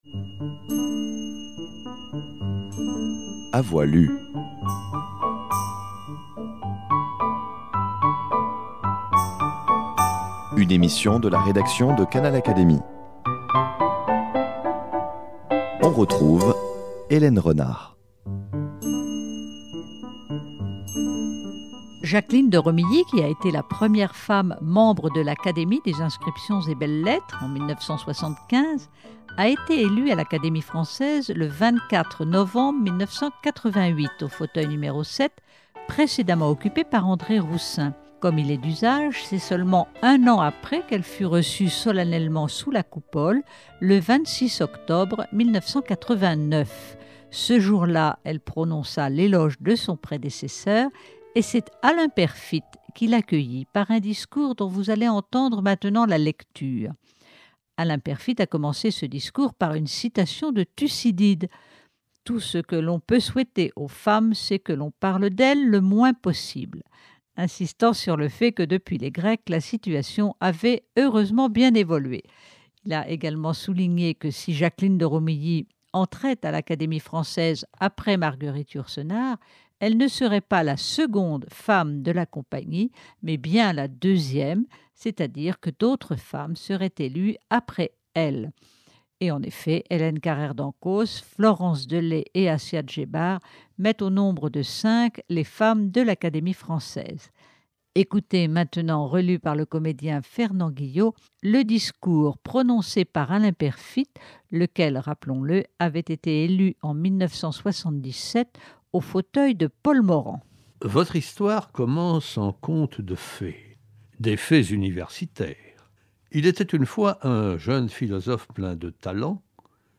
l’essentiel de ce discours relu par le comédien